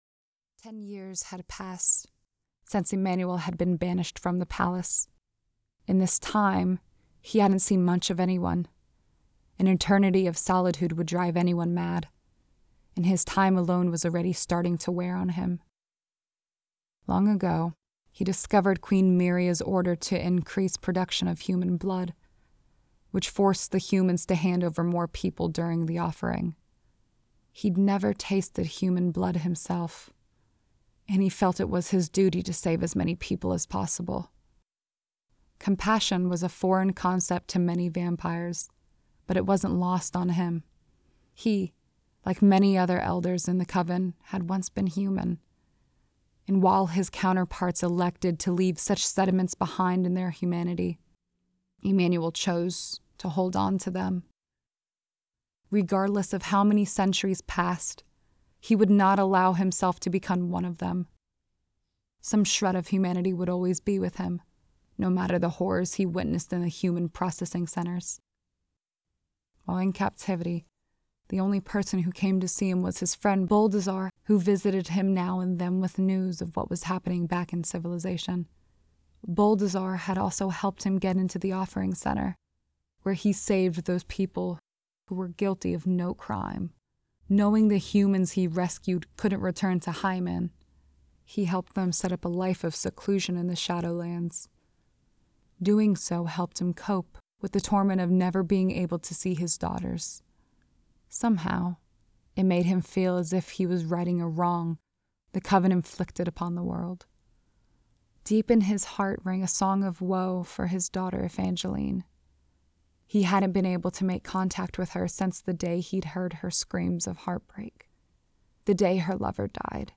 Voyages Audiobook (The Blood's Passion Saga Book 3)